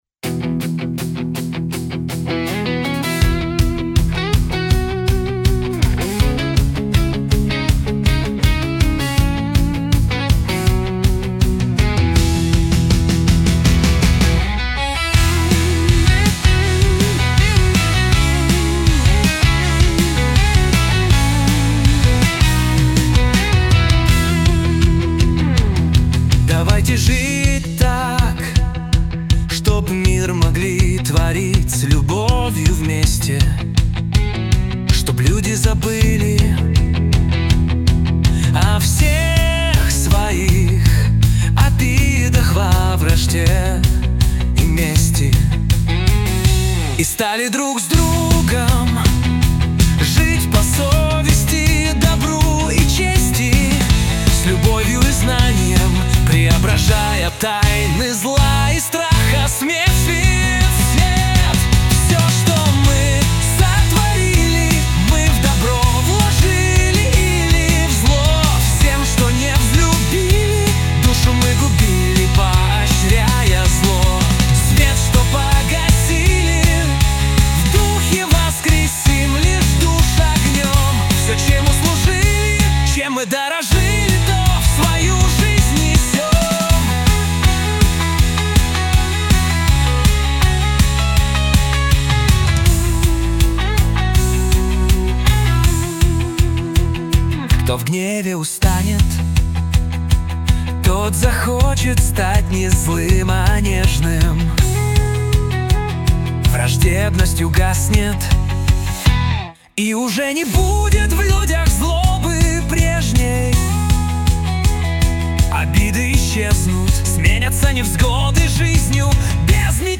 кавер-врсия